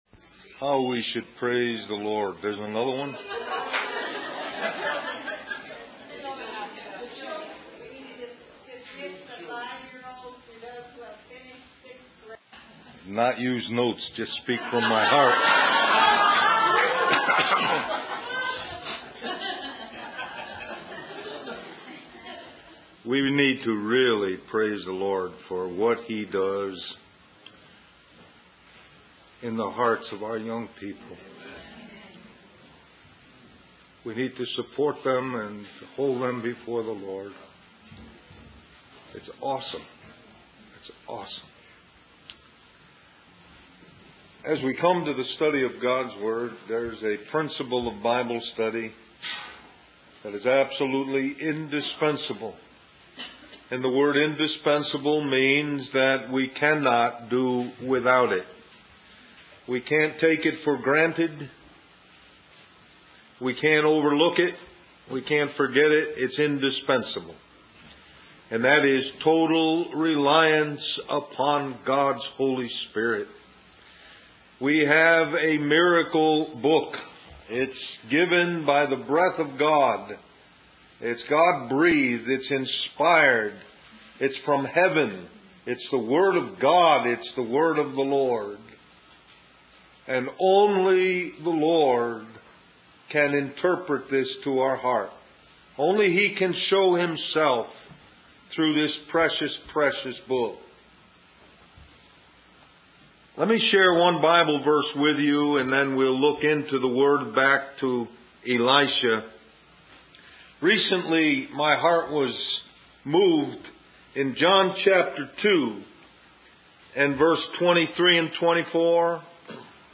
Del Mar Va Mens Retreat 2002 List